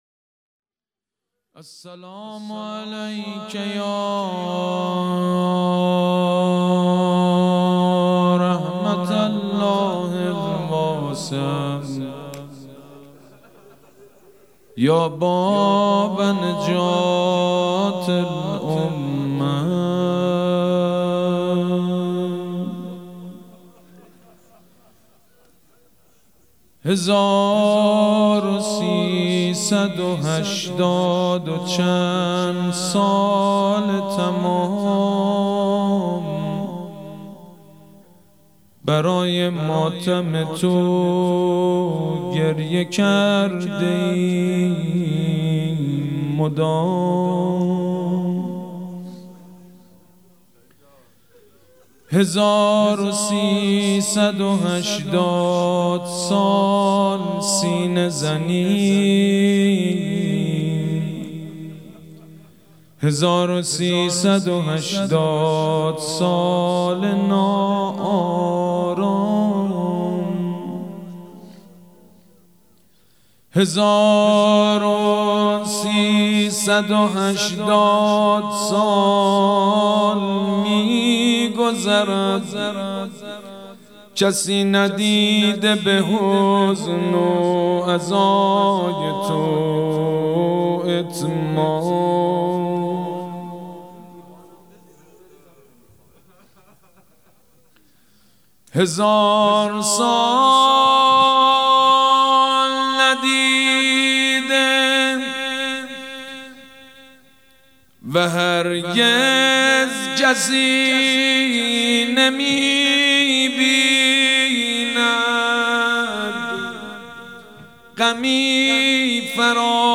مداحی شب اول محرم 1399 با نوای حاج سید مجید بنی فاطمه
روضه- هزاروسيصدو هشتاد و چند ساله تمام